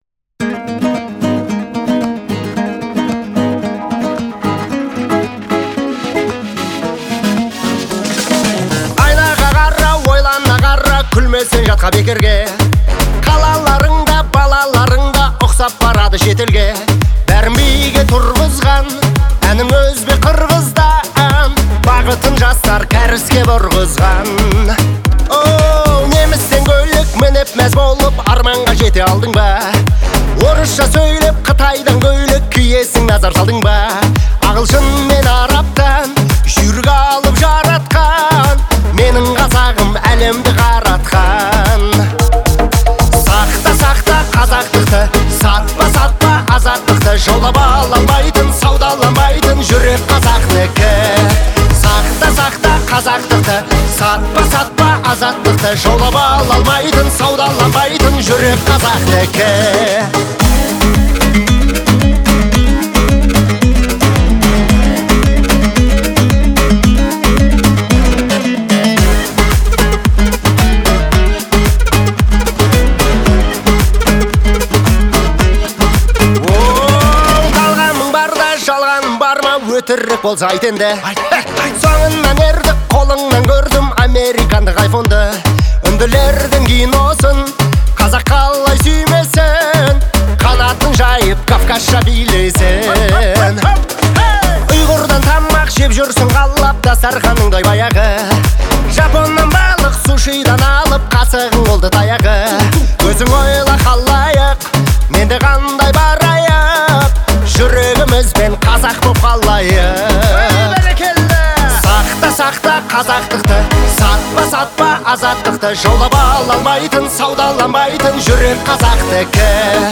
это вдохновляющая песня в жанре поп